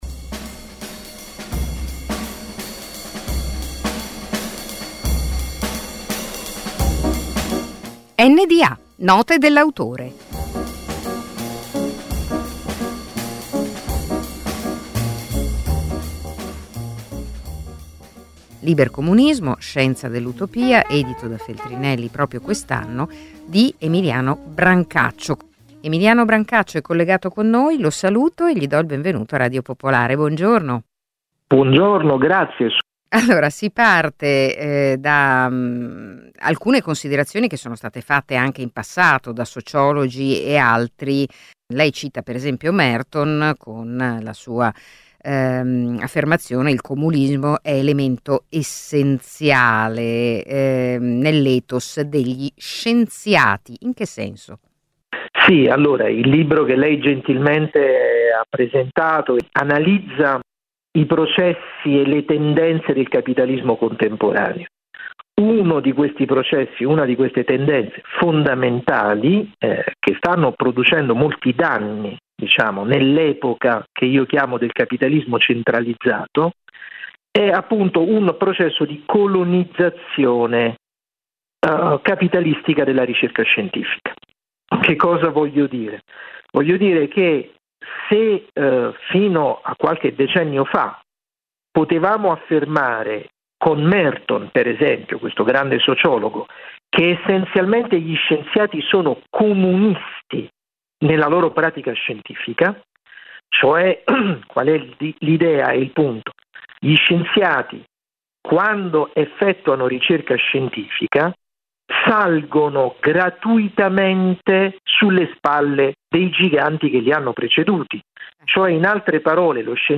Un appuntamento quasi quotidiano, sintetico e significativo con un autore, al microfono delle voci di Radio Popolare. Note dell’autore è letteratura, saggistica, poesia, drammaturgia e molto altro.